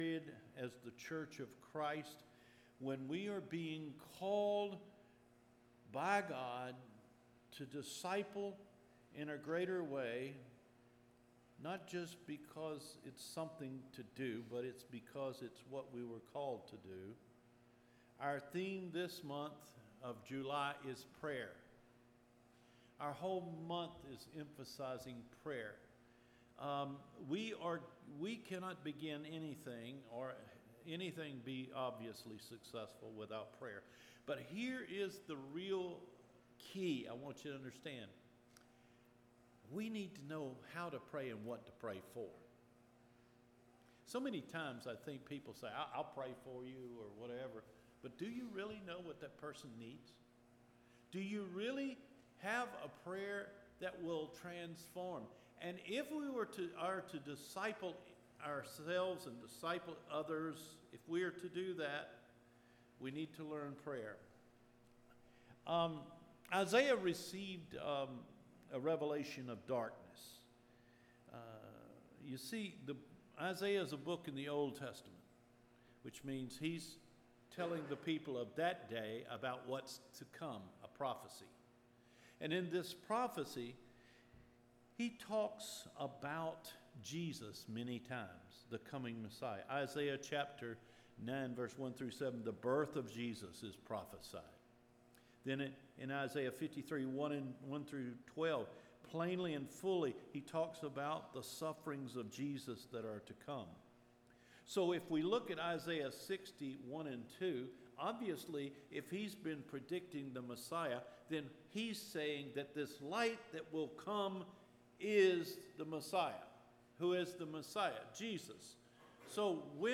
JULY 4 SERMON – OUT OF THE DARKNESS LET YOUR LIGHT SHINE
Recorded Sermons